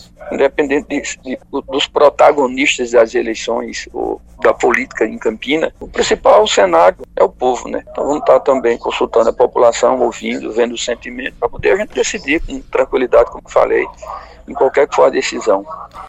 Os comentários do parlamentar foram registrados pelo programa Correio Debate, da 98 FM, de João Pessoa, nesta terça-feira (06/02).